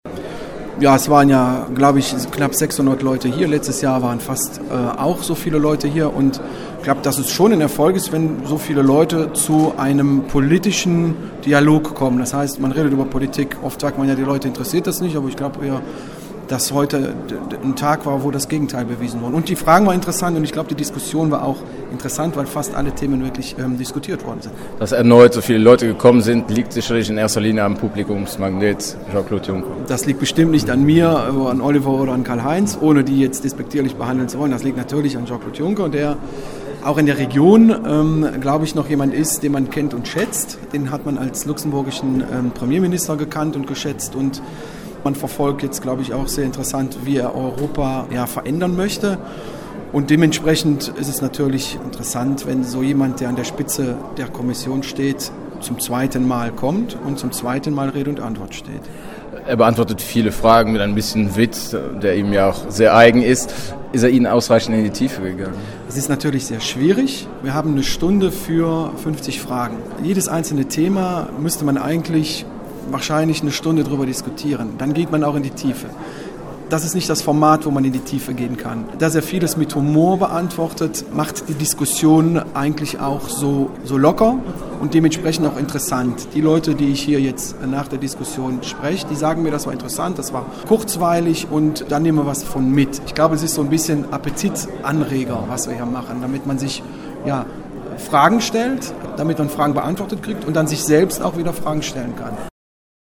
Der zweite Bürgerdialog mit Jean-Claude Juncker in St.Vith fand am Mittwochabend vor rund 600 Besuchern statt. Das zeige, dass ein gewisses politisches Interesse bei der Bevölkerung vorhanden sei, freute sich der ostbelgische EU-Parlamentarier Pascal Arimont: